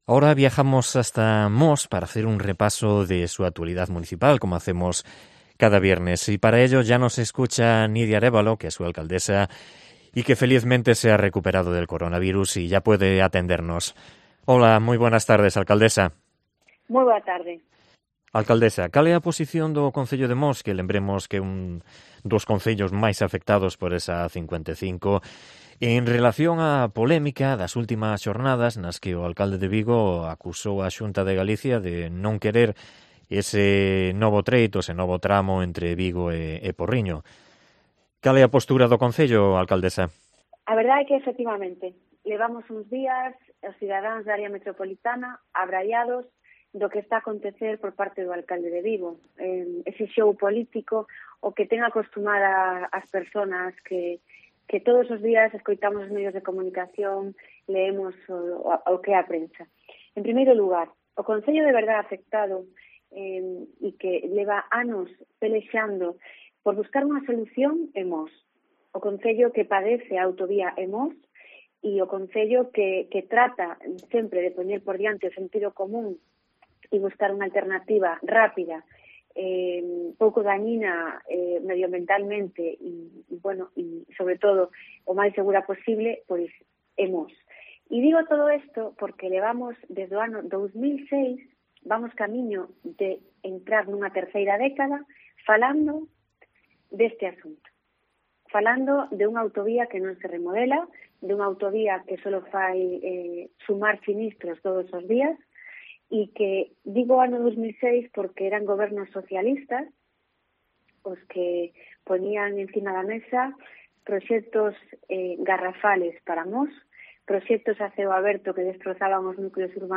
Entrevista a Nidia Arévalo, alcaldesa de Mos